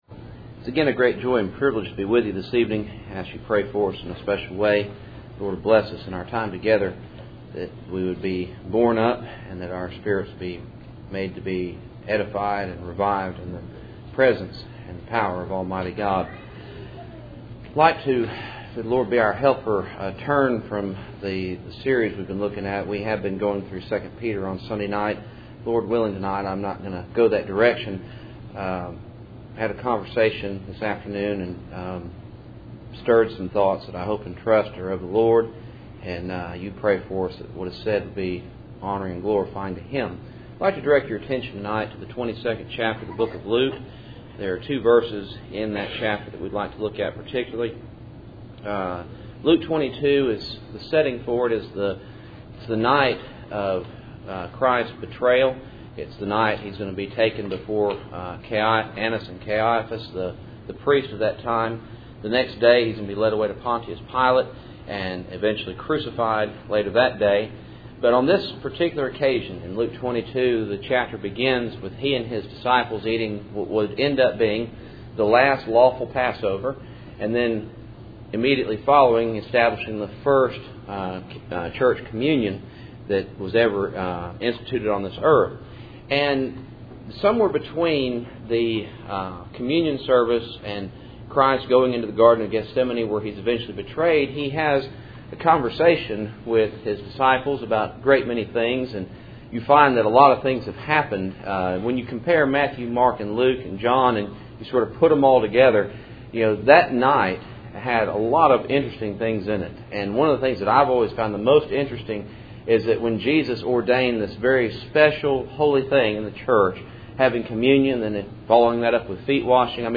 Passage: Luke 22:31-32 Service Type: Cool Springs PBC Sunday Evening %todo_render% « The Kingdom in Power